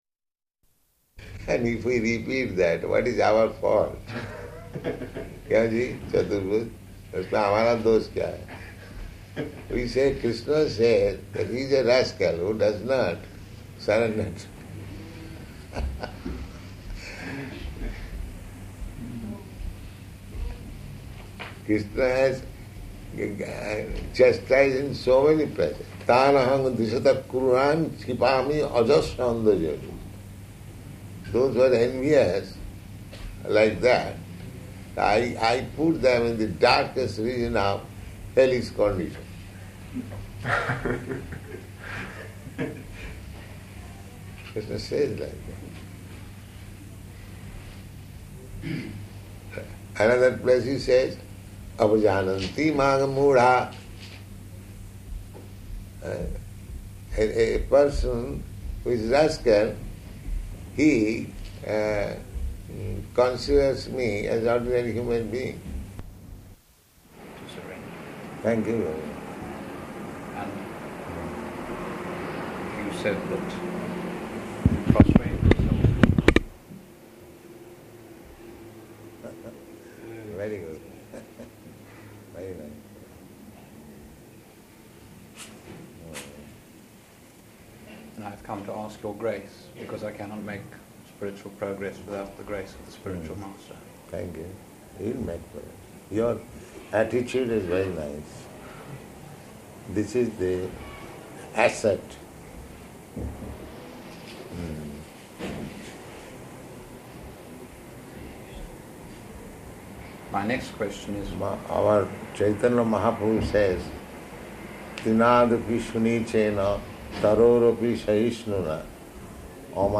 -- Type: Conversation Dated: August 2nd 1972 Location: London Audio file